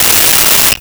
Cell Phone Ring 09
Cell Phone Ring 09.wav